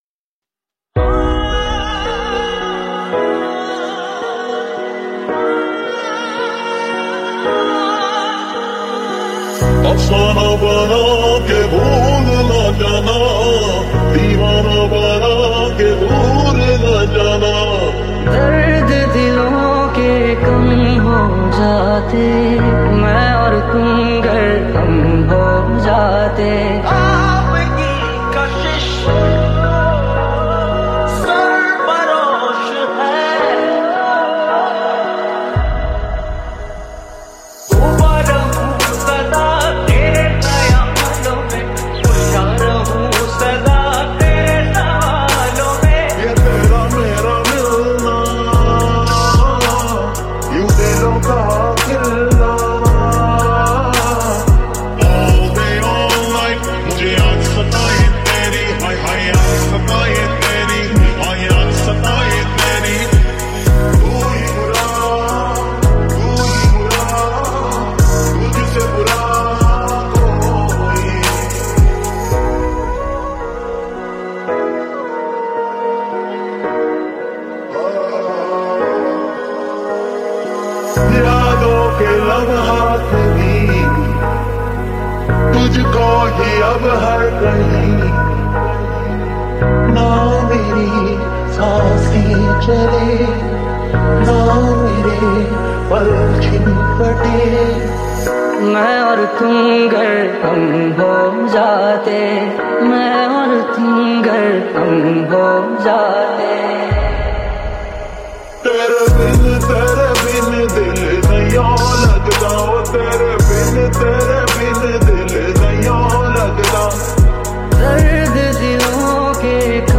New New Dj Song 2023